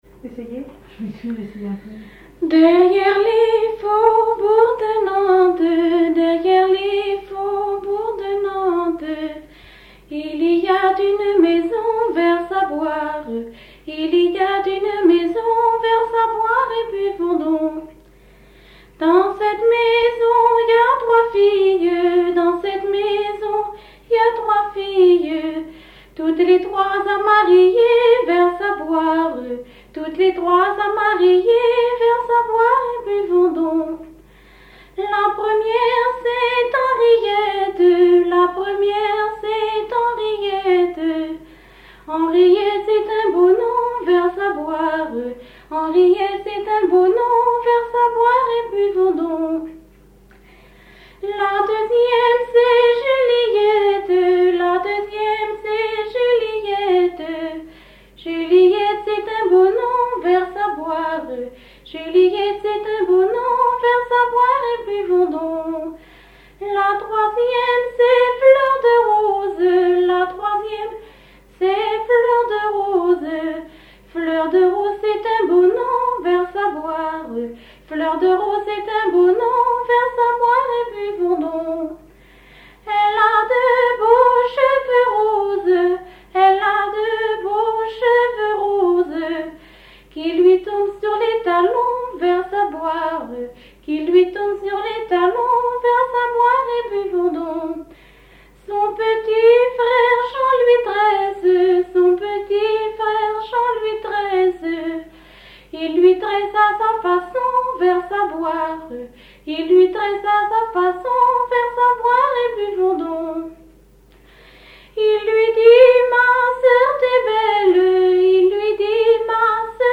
Base d'archives ethnographiques
Genre laisse
Catégorie Pièce musicale inédite